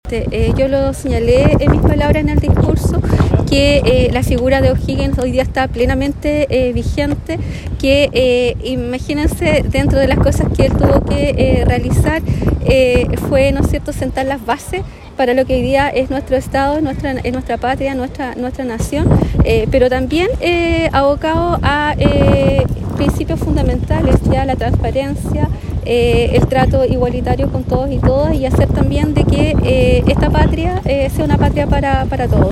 Con un acto realizado en Plaza de Armas, que consideró la colocación de ofrendas, Osorno conmemoró el natalicio número 245 del Libertador Bernardo O’Higgins Riquelme, que ocupa un sitial importante en nuestra historia, al participar en la gesta emancipadora para la independencia de Chile.
La Delegada Presidencial enfatizó en que estas bases se mantienen vigentes, como el trato igualitario que convierte a Chile en una patria para todos.